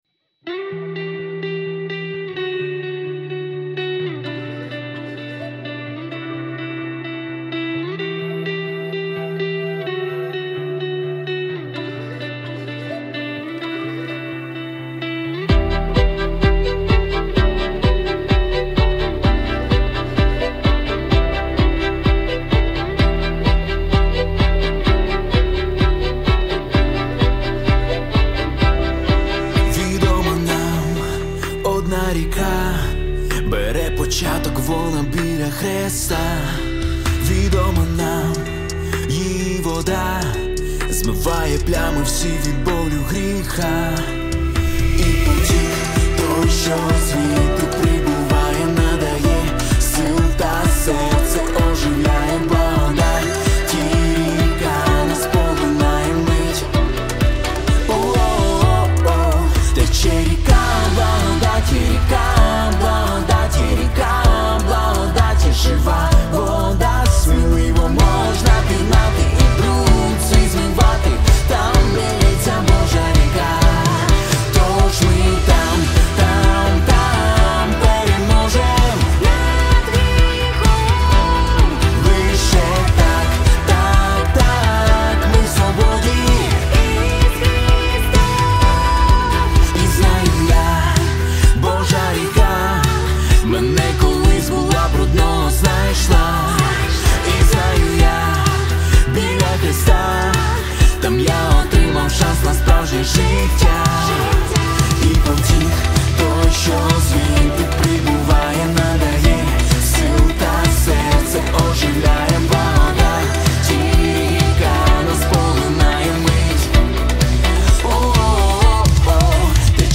48833 просмотра 19222 прослушивания 4508 скачиваний BPM: 128